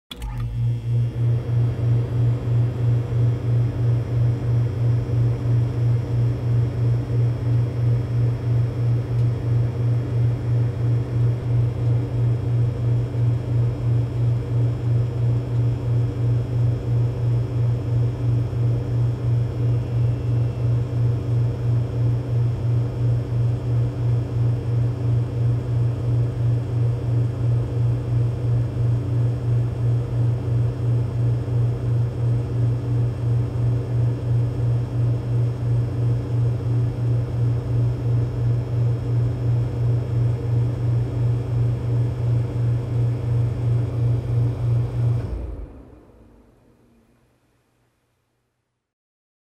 На этой странице собраны звуки работающего холодильника: от монотонного гула до характерных щелчков и бульканья хладагента.
Звук запуска холодильника